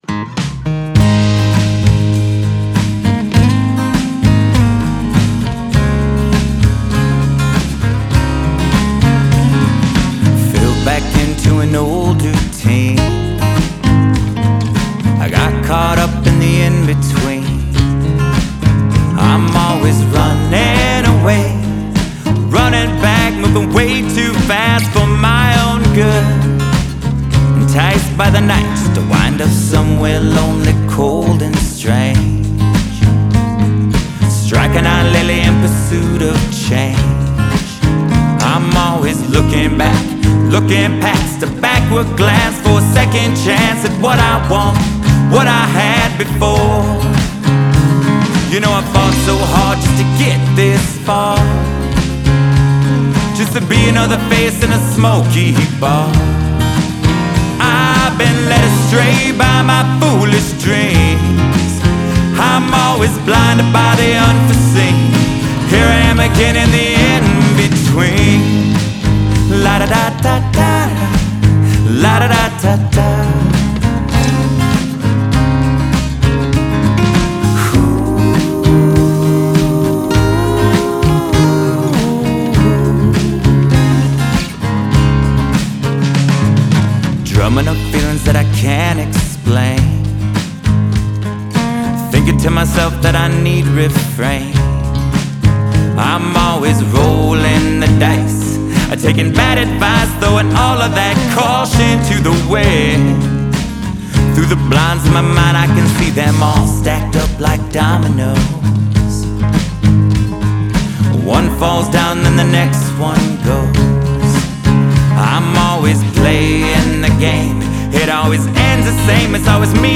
A moody midtempo Americana song about being newly single.